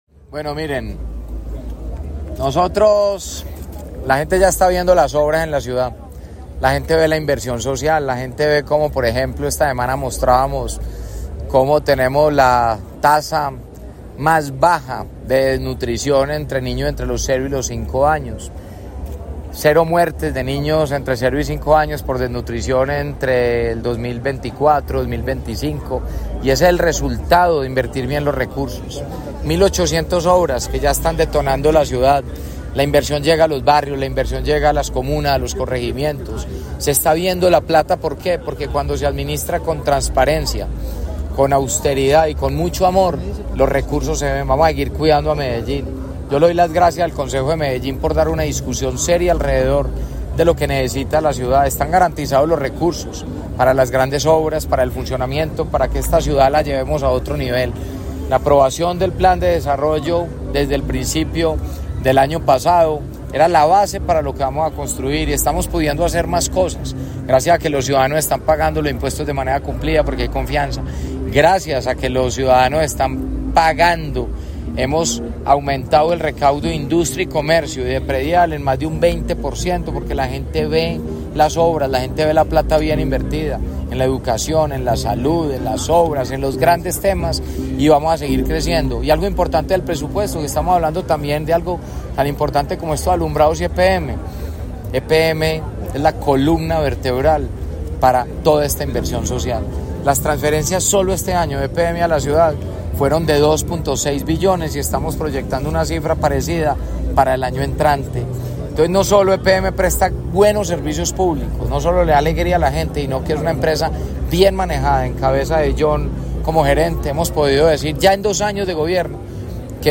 Declaraciones-del-alcalde-de-Medellin-Federico-Gutierrez-1.mp3